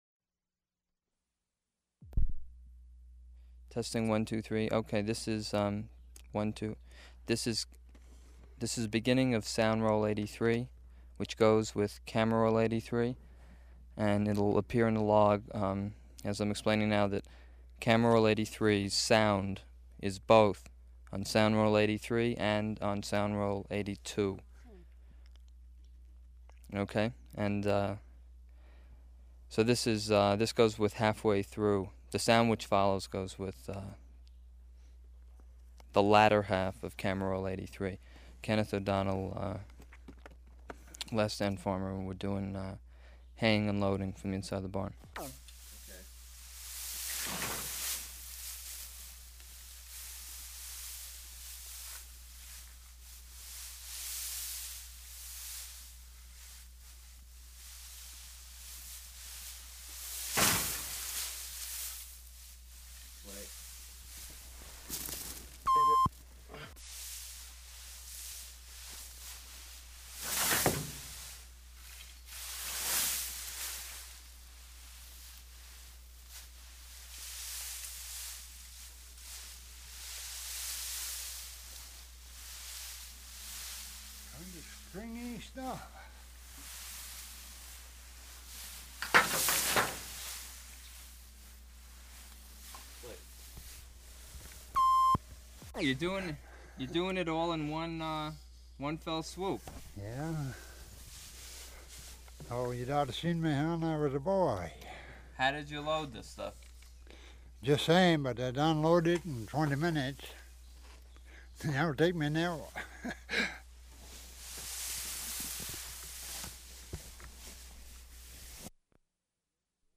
(Interviewer)
Format 1 sound tape reel (Scotch 3M 208 polyester) : analog ; 7 1/2 ips, full track, mono.